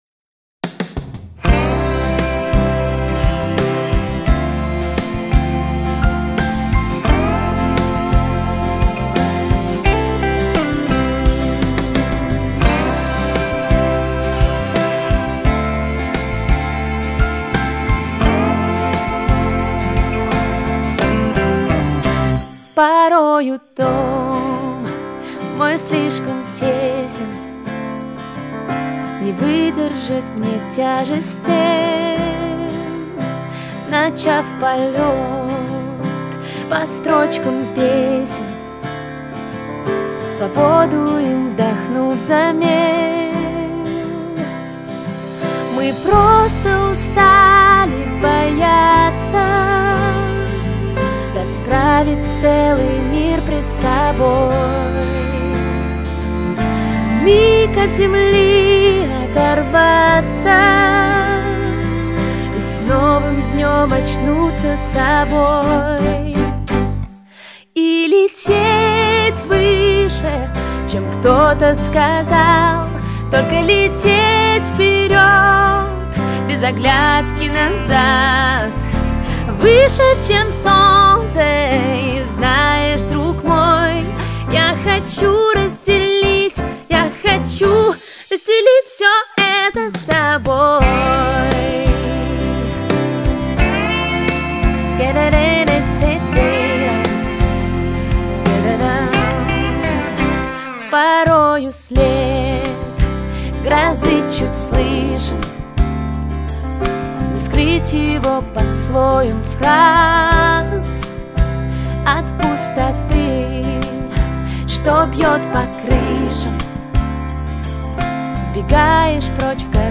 рояль, вокал
барабаны
бас
гитары
струнные